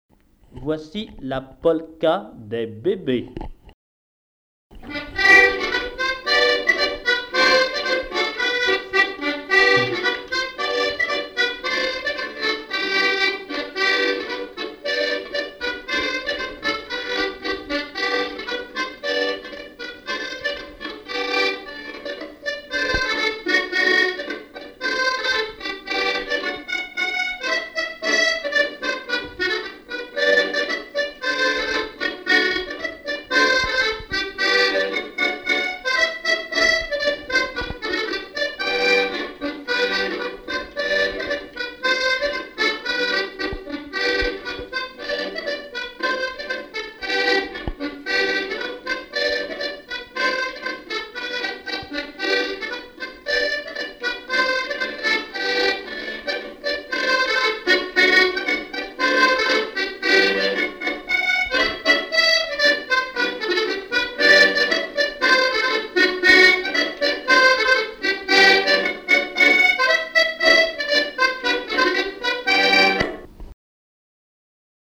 Bournezeau
danse : polka des bébés ou badoise
Pièce musicale inédite